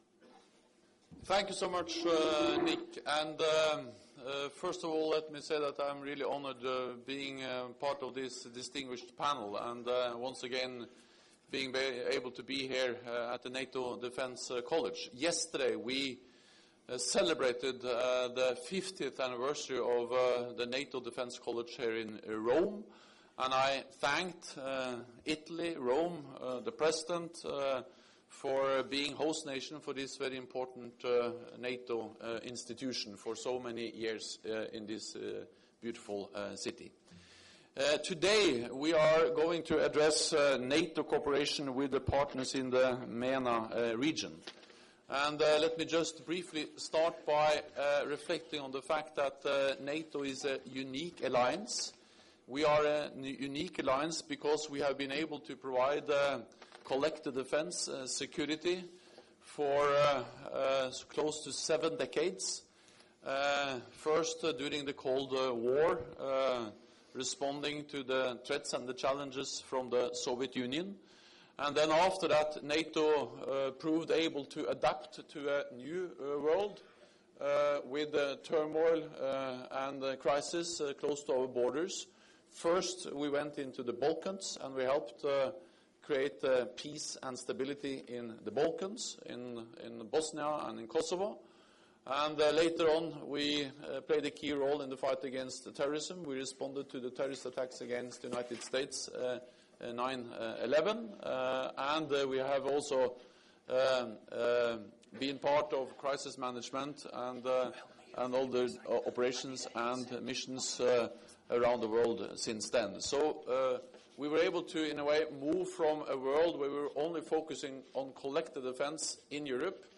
Remarks by NATO Secretary General Jens Stoltenberg at the NATO Defense College conference ''NATO cooperation with Partners in the Mediterranean and the Middle East''